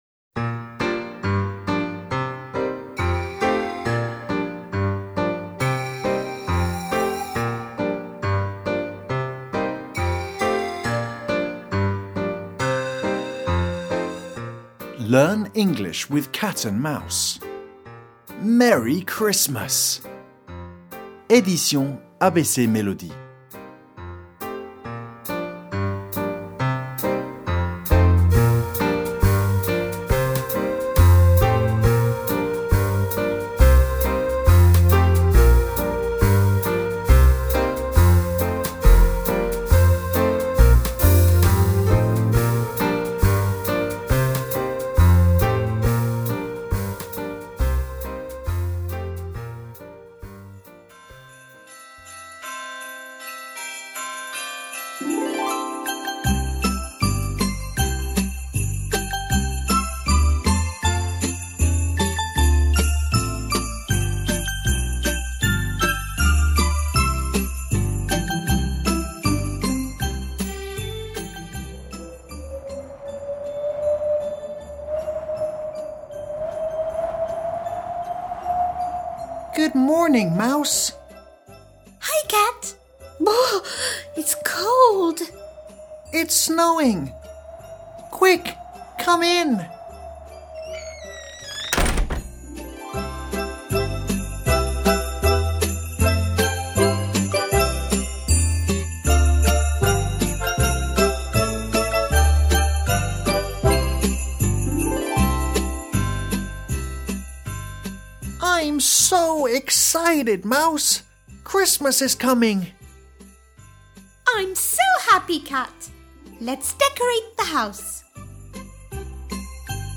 Merry Christmas, Cat and Mouse! LEARN ENGLISH WITH CAT AND MOUSE, une collection en 3 niveaux pour apprendre l’anglais en s’amusant de la Maternelle au CM, avec l’audio accessible par QR Code dans le livre: l’histoire et des jeux audio pour bien prononcer !